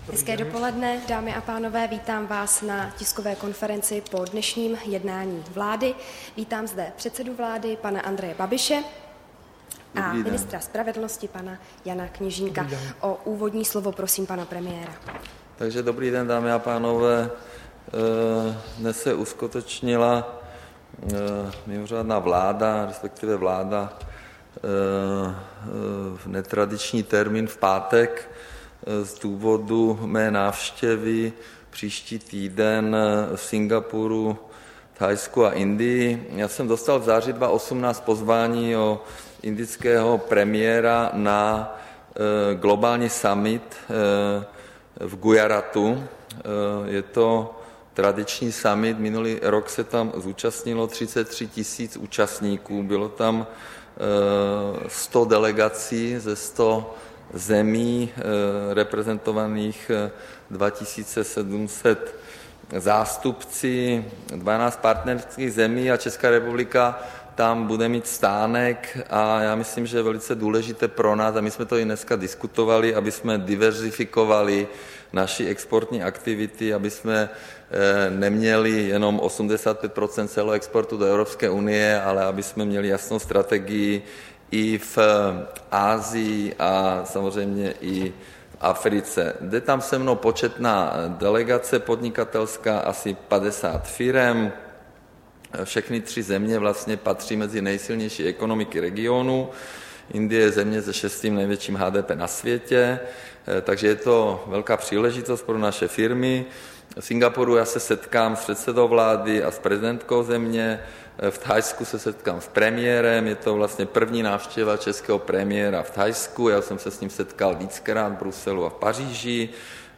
Tisková konference po jednání vlády, 11. ledna 2019